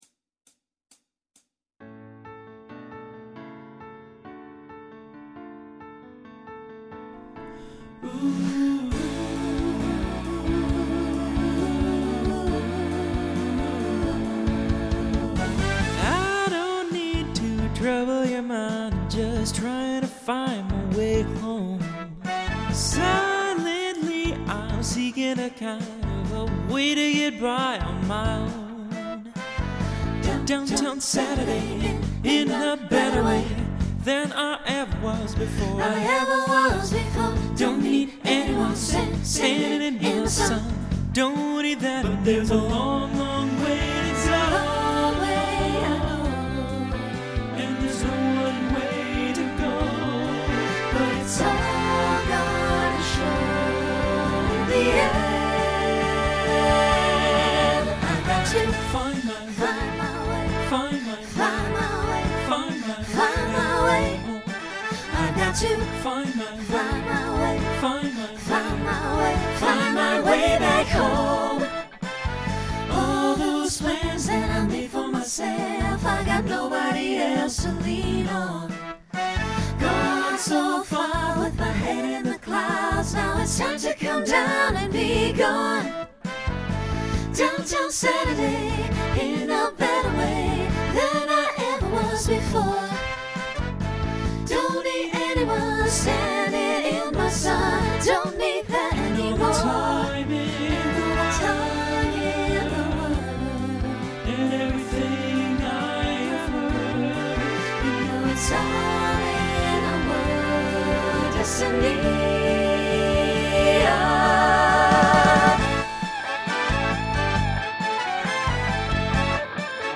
Genre Rock Instrumental combo
Mid-tempo Voicing SATB